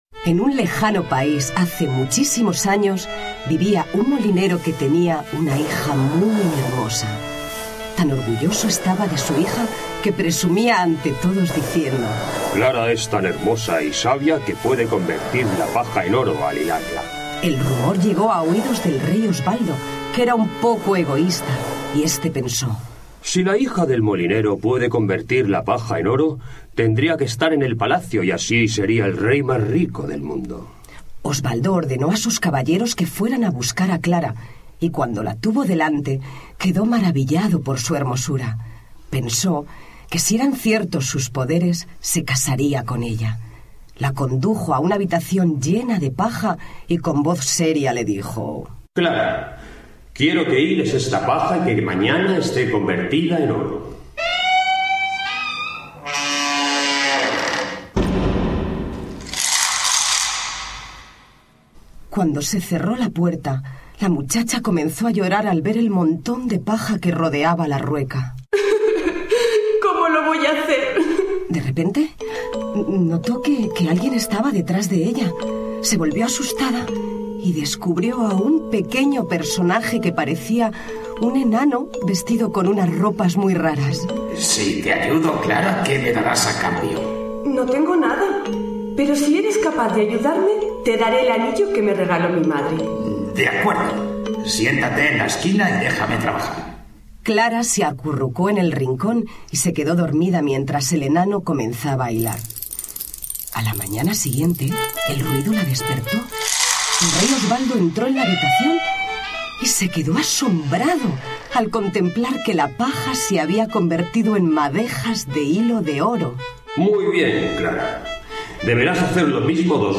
Sonidos: Cuentos infantiles